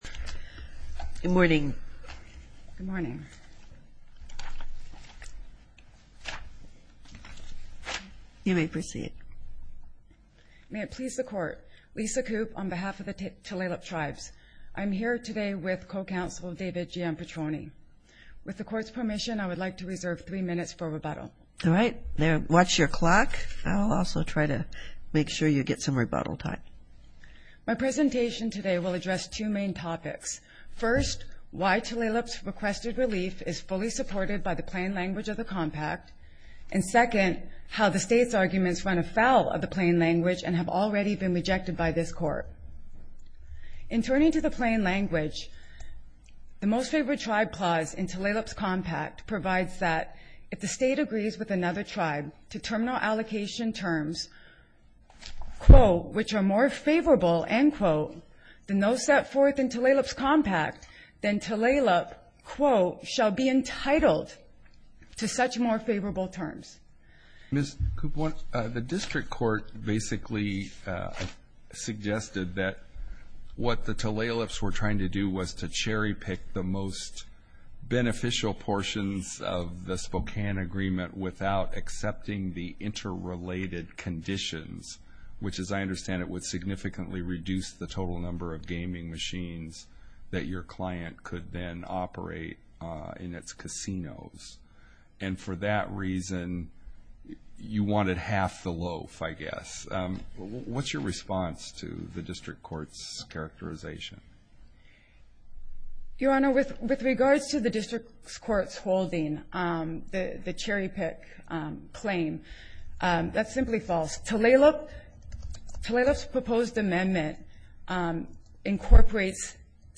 Here are the briefs: Tulalip Opening Brief Samish Amicus Brief Washington Brief Tulalip Reply Oral argument audio here .